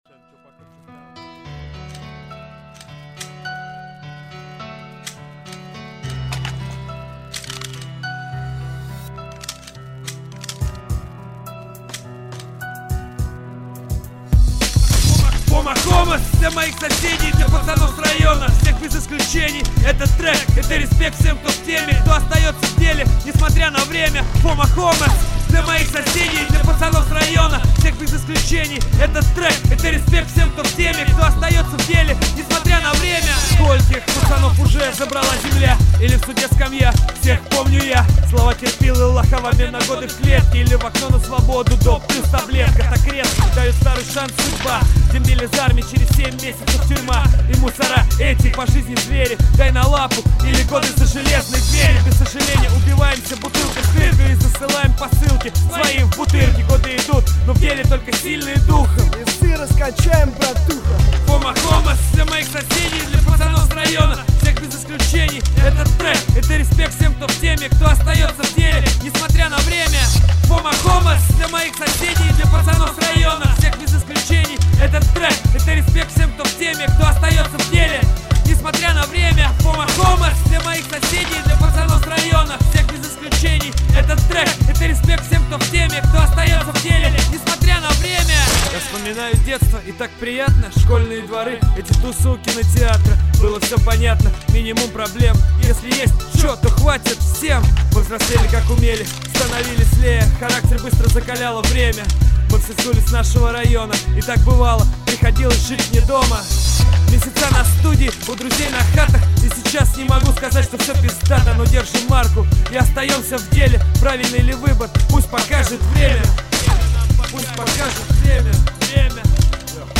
бля гавнорэп, я считаю, никак не подходит для мува, нужно динамическое звучание трека, а это подойдет для какого то грустного видео